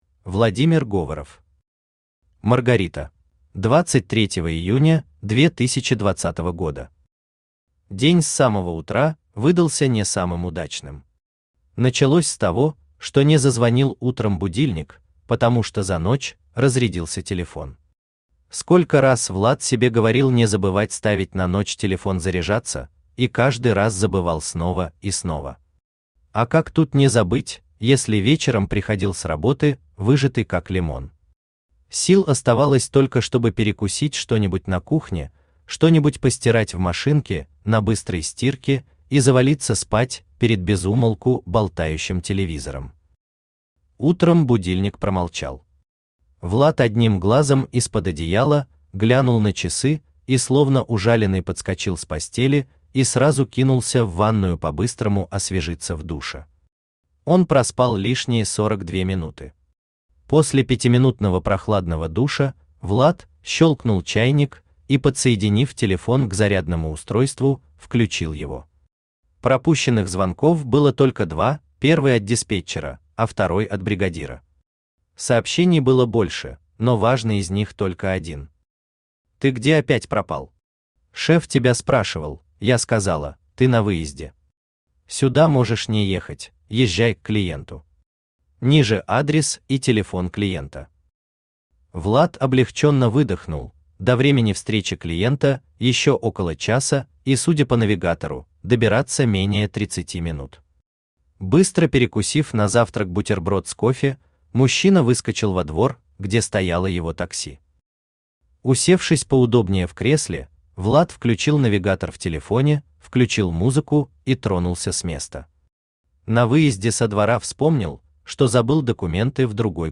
Аудиокнига Маргарита | Библиотека аудиокниг
Aудиокнига Маргарита Автор Владимир Говоров Читает аудиокнигу Авточтец ЛитРес.